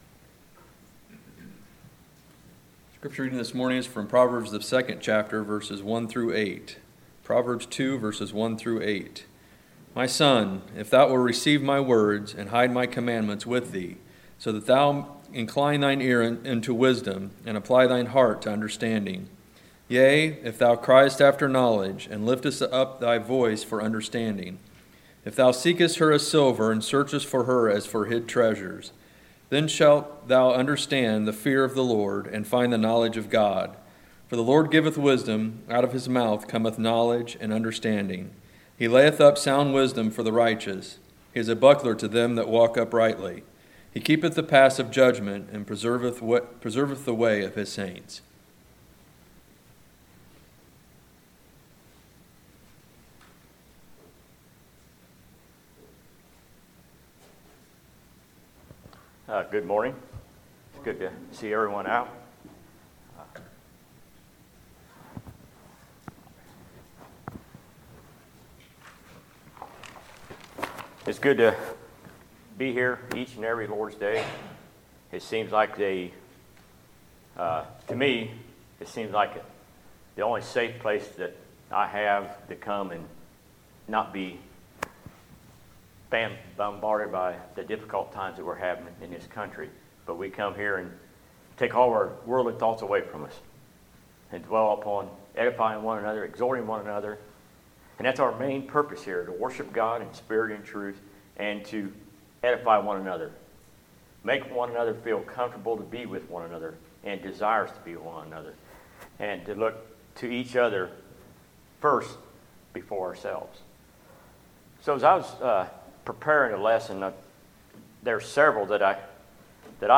Sermons, July 12, 2020